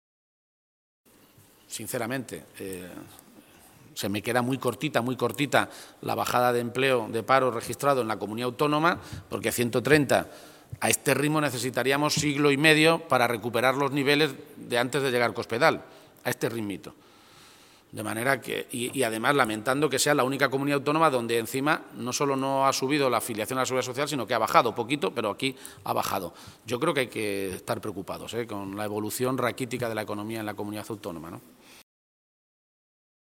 Lo ha hecho en una comparecencia ante los medios de comunicación, en Toledo, en la que ha valorado los datos de paro registrados en las oficinas del INEM correspondientes al mes de marzo.
Cortes de audio de la rueda de prensa